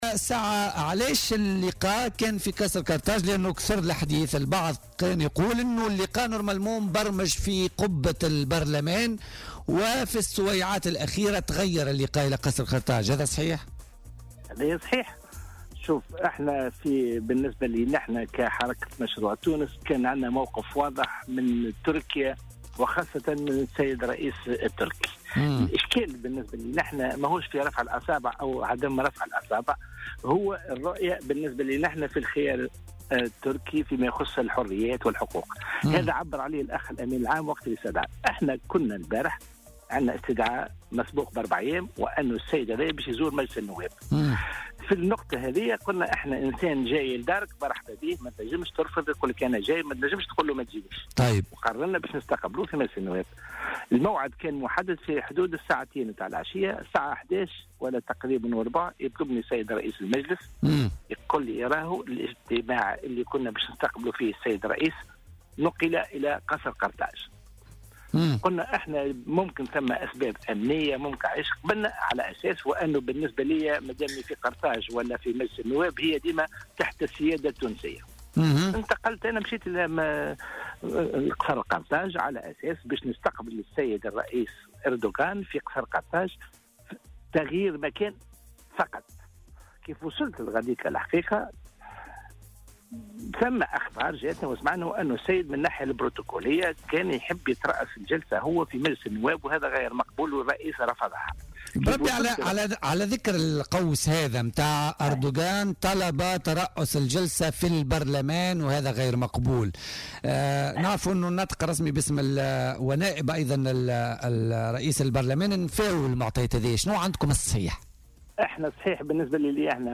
وأوضح في مداخلة له اليوم في برنامج "بوليتيكا" أن "الموازين انقلبت" وتم تخصيص قاعة للرئيس التركي لاستقبال النواب عوض أن يحدث العكس، كما أن الفريق الأمني الخاص بالرئيس التركي هو الذي قام بتأمين القاعة بدلا عن الأمن التونسي، وهو ما اعتبره النائب "مسّ من السيادة الوطنية".